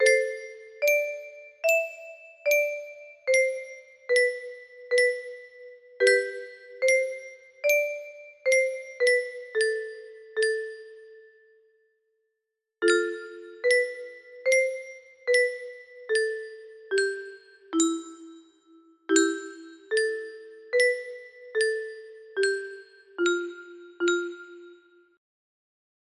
petite comptine